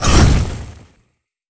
ice_blast_projectile_spell_04.wav